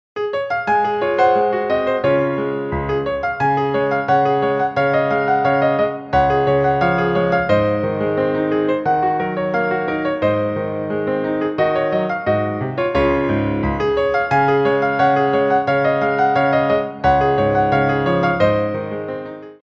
Petit Allegro 1
4/4 (16x8)